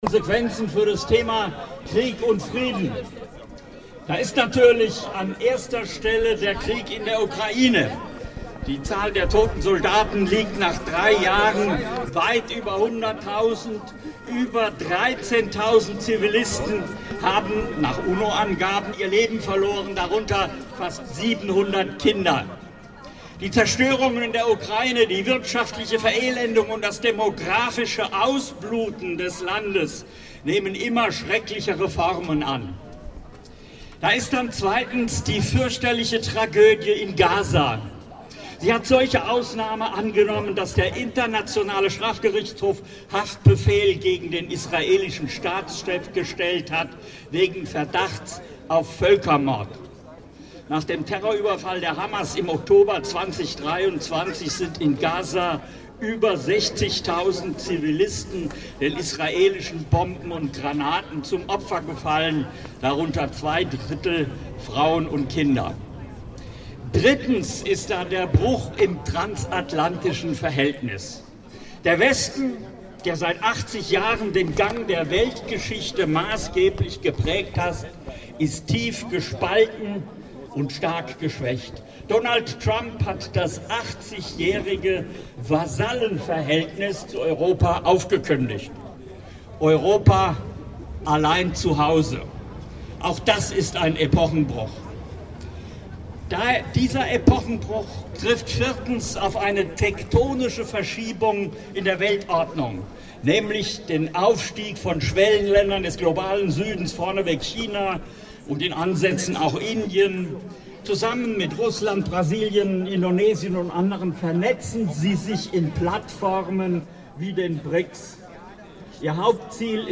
11.Dezember 2024, Buch-Oase, Kassel